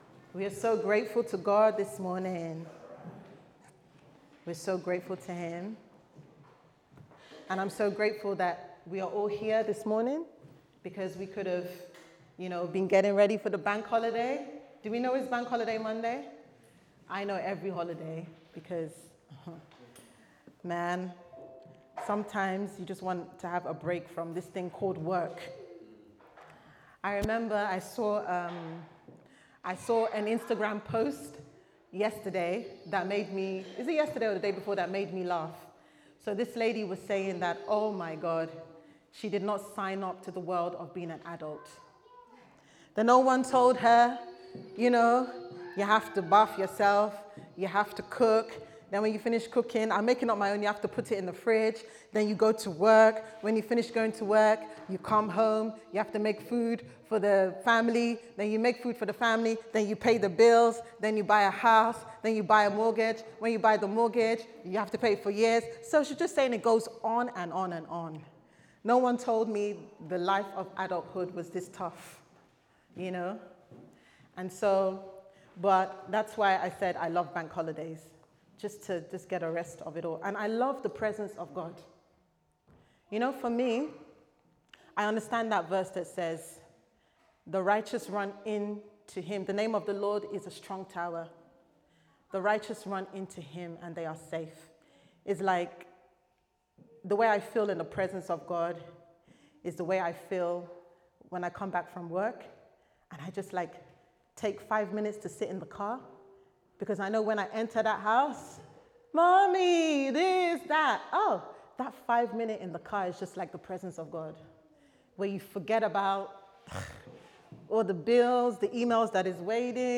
How Can I Find God's Will For My Life Service Type: Sunday Service Sermon « How Do I Find And Follow God’s Plans For My Life